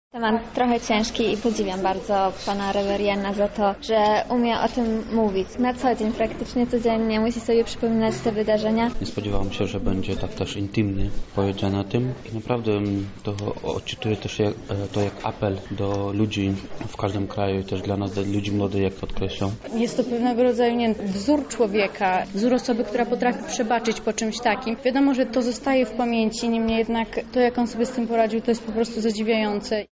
Na wczorajszym spotkaniu pojawiło się kilkanaście osób. Zapytaliśmy ich o wrażenia.